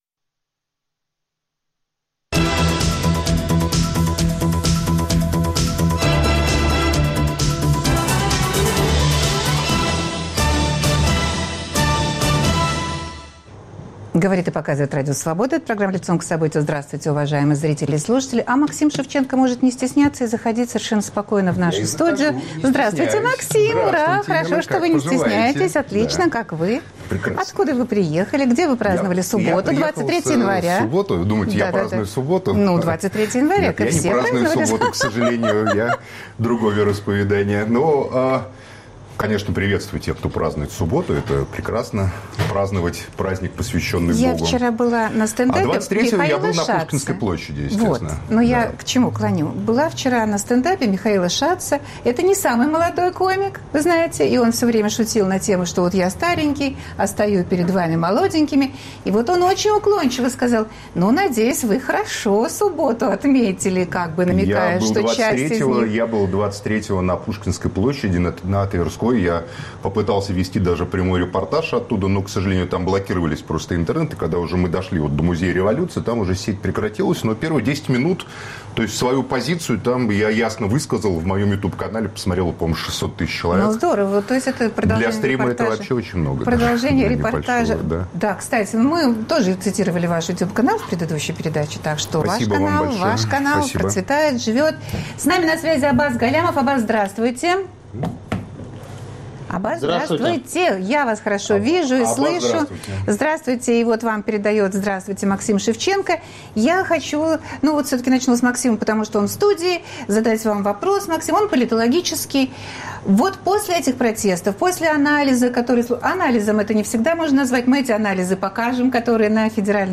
Каким образом власть, похоже стремительно теряющая авторитет, будет пытаться себе его возвращать? Обсуждают журналист Максим Шевченко и политолог Аббас Галямов.